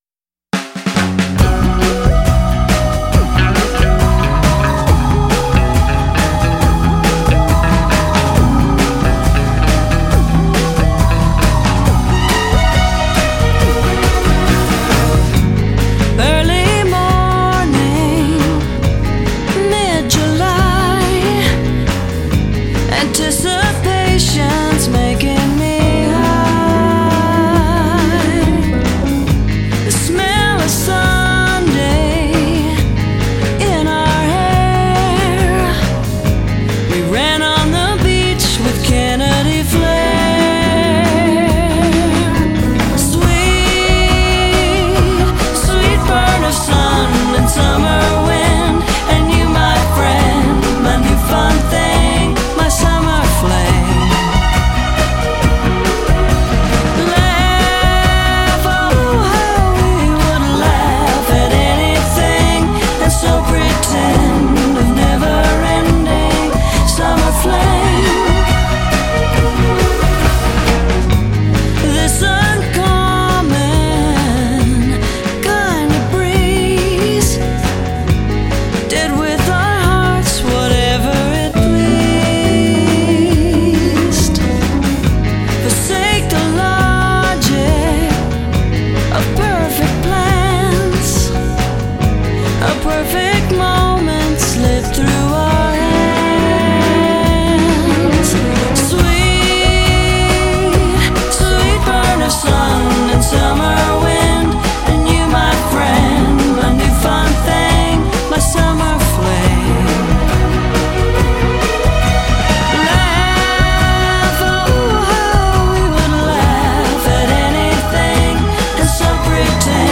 A balmy, breezy bit of chanteusery